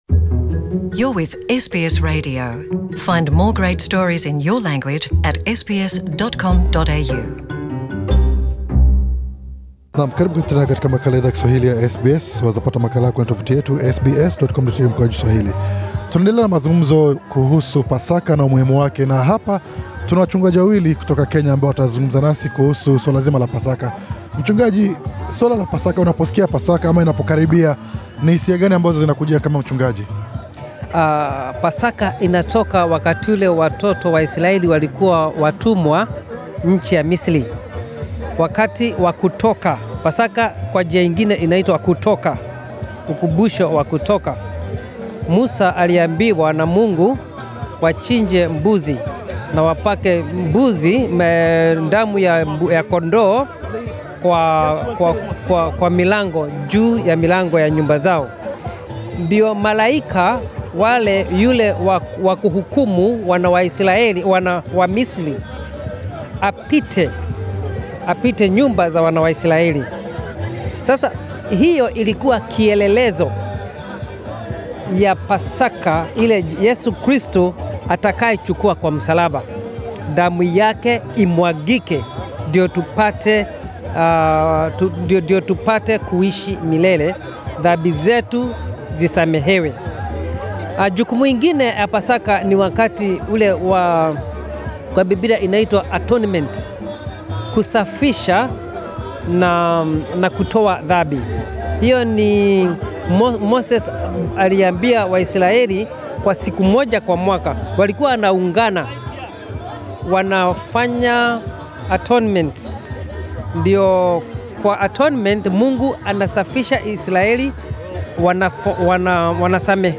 alifanya mazungumzo